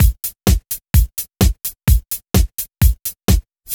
VMH1 Minimal Beats 09.wav